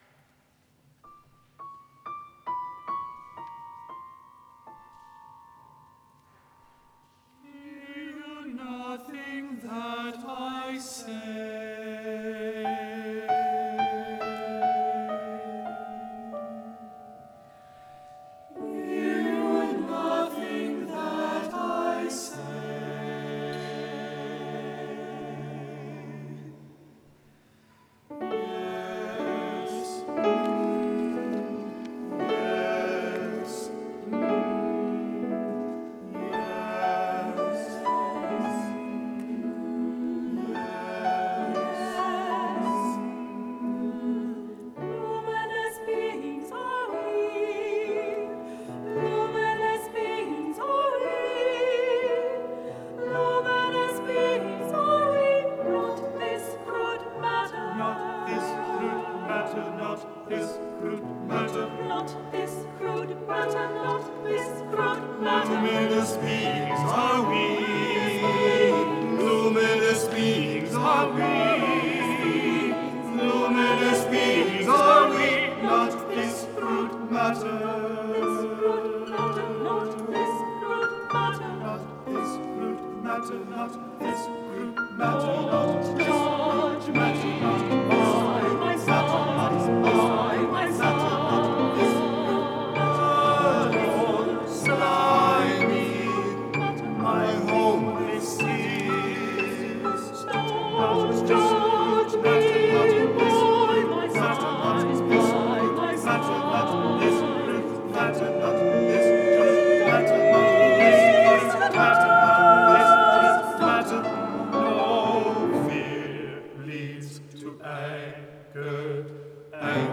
choral piece
It was performed on May 4th, 2014.